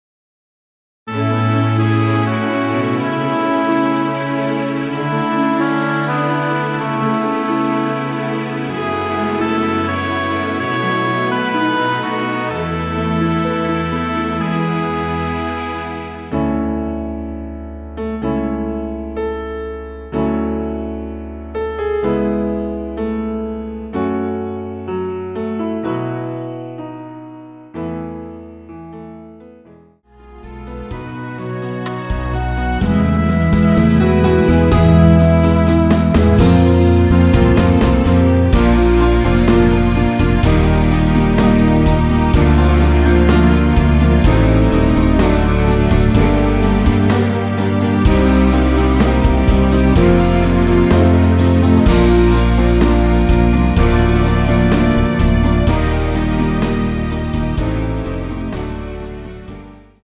음정은 반음정씩 변하게 되며 노래방도 마찬가지로 반음정씩 변하게 됩니다.
앞부분30초, 뒷부분30초씩 편집해서 올려 드리고 있습니다.